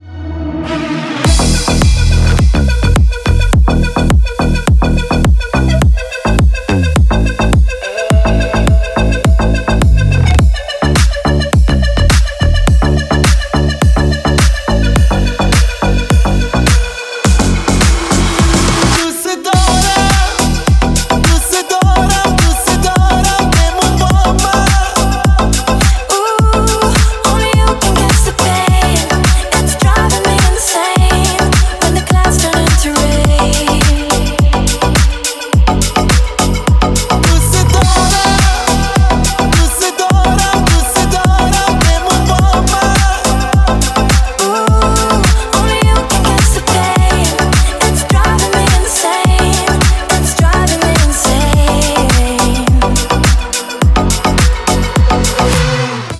• Качество: 128, Stereo
ритмичные
house
Красивый ремикс